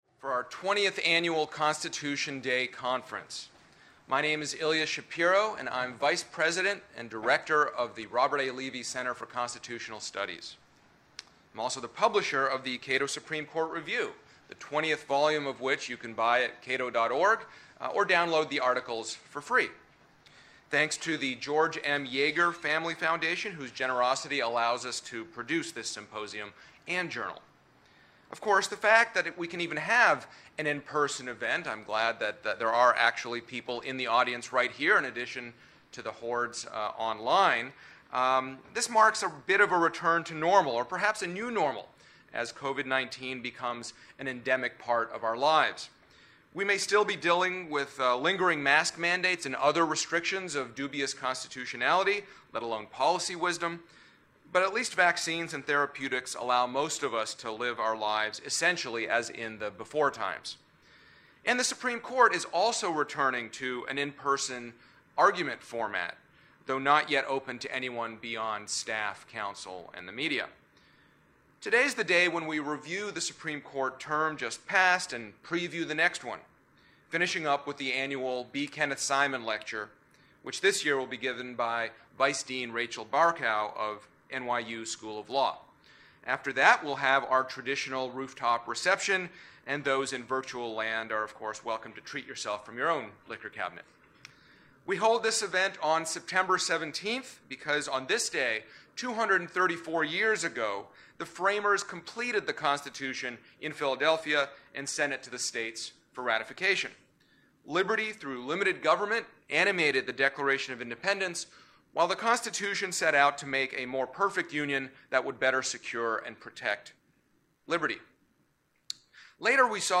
20th Annual Constitution Day: Welcoming Remarks and Panel 1: First Amendment
Cato’s annual Constitution Day symposium marks the day in 1787 that the Constitutional Convention finished drafting the U.S. Constitution. We celebrate that event each year with the release of the new issue of the Cato Supreme Court Review and with a day‐long symposium featuring noted scholars discussing the recently concluded Supreme Court term and the important cases coming up.